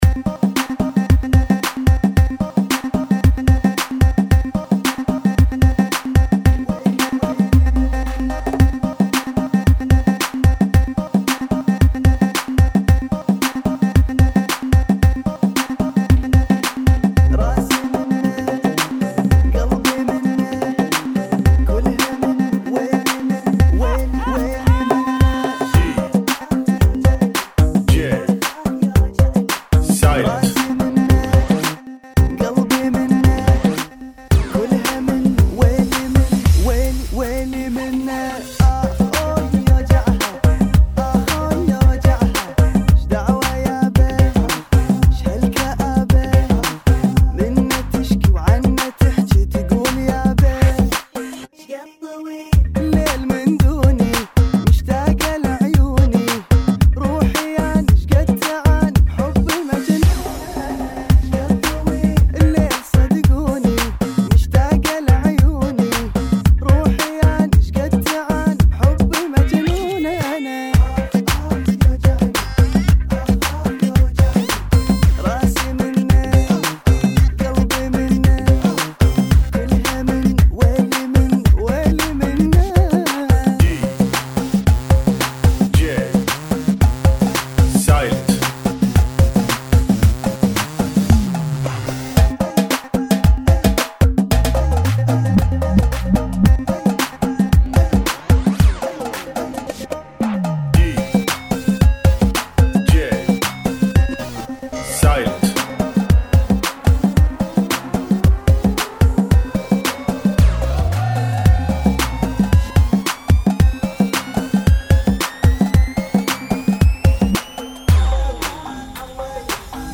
112 BPM